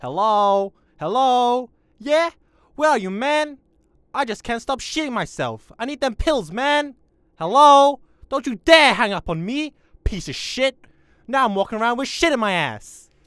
Voice Lines / Street barklines
hello HELLO where are you man i cant stop shitting myself.wav